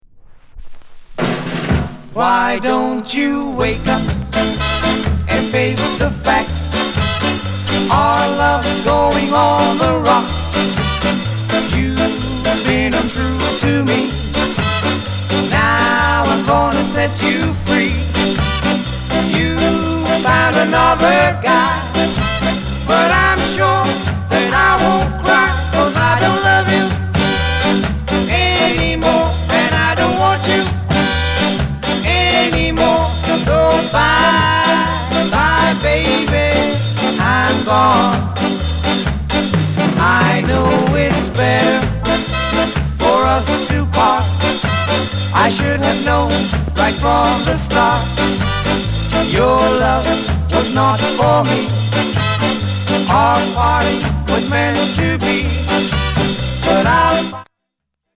RARE NICE SKA